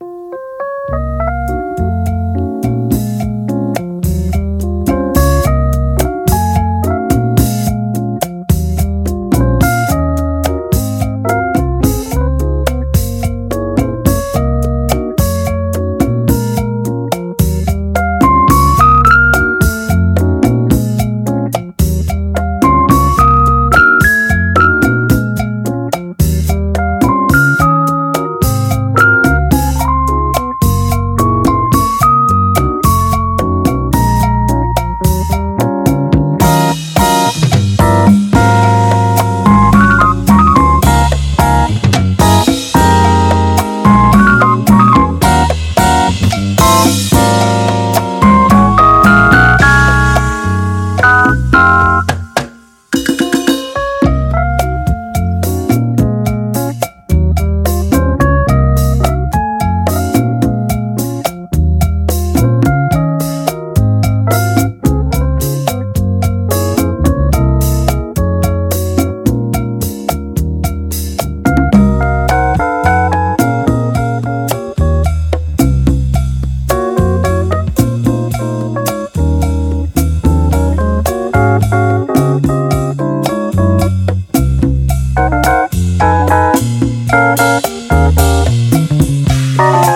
ROCK / 70'S / FOLK ROCK / ACID FOLK
フォーク・ロックの枠を越え、ジャズやワールド要素も織り込んだ豊かなアレンジが魅力です。